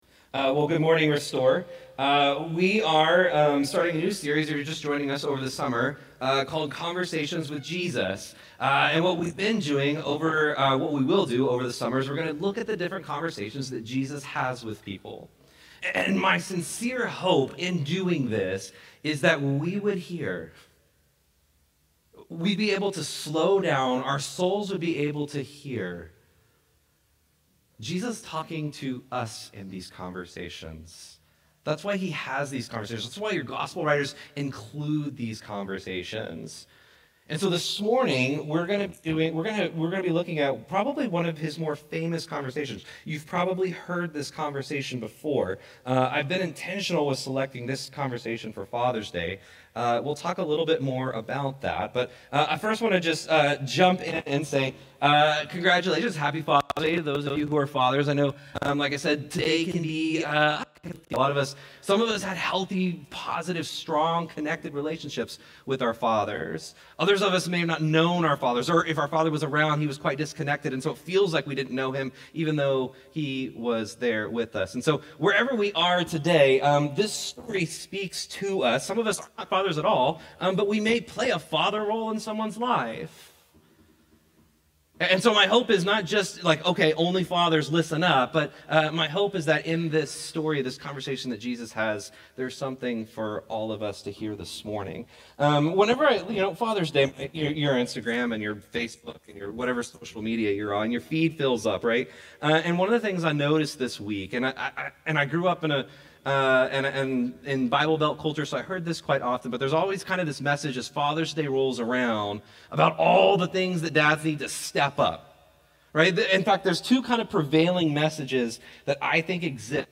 Restore Houston Church Sermons